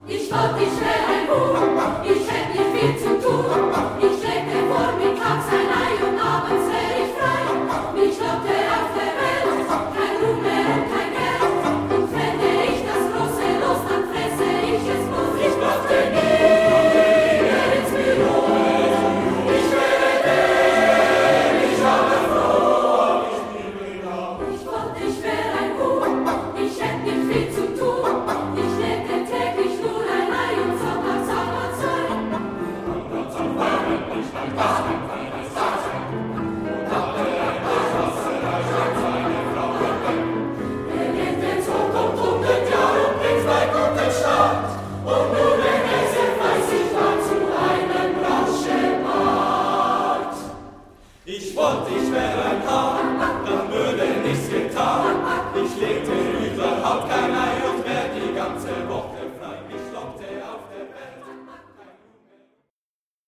Klavier , SATB